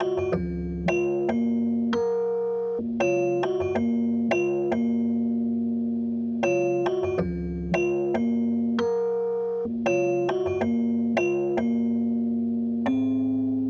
vibra_r.wav